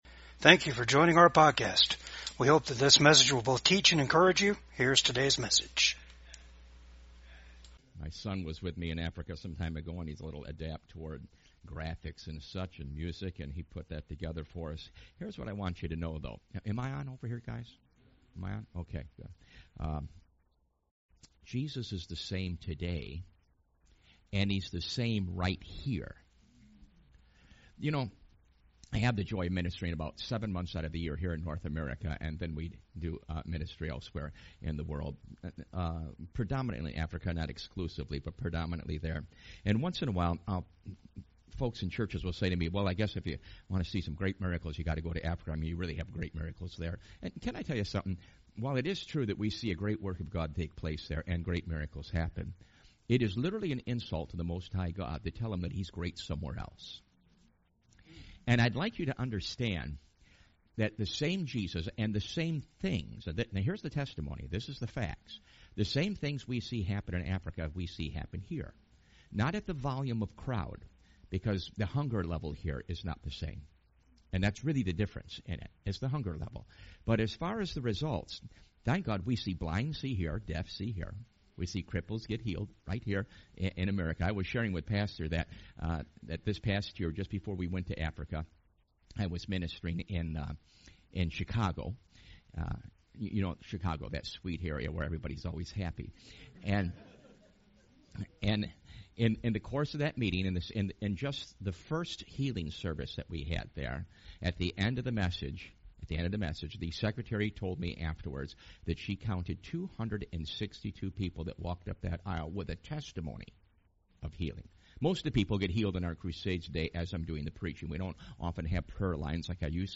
Service Type: REFRESH SERVICE Topics: Christ , healing , miracles , The Holy Spirit